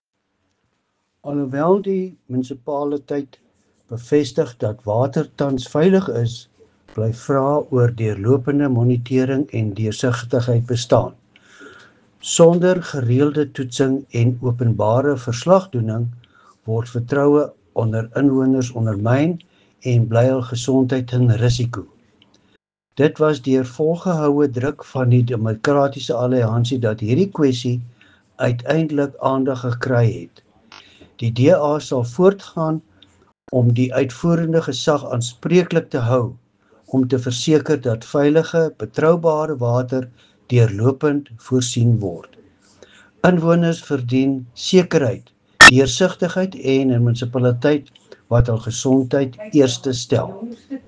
Afrikaans soundbite by Cllr Louis van Heerden